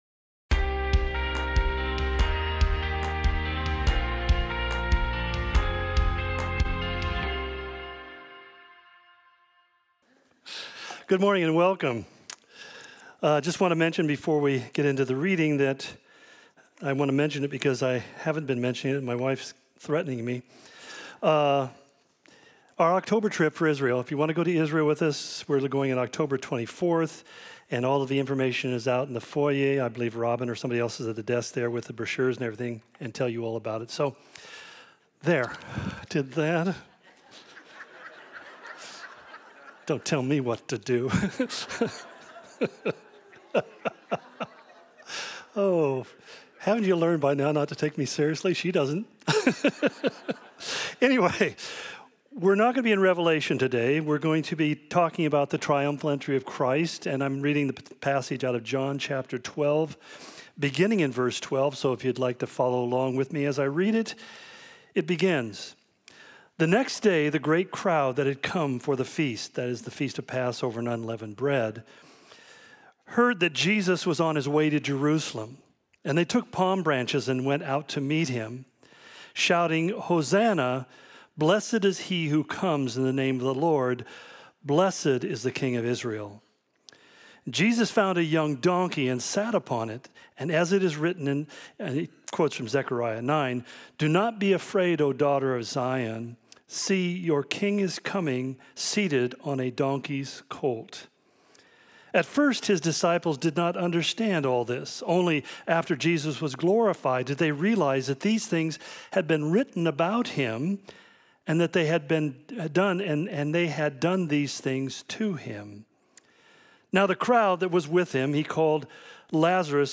Similar to Calvary Spokane Sermon of the Week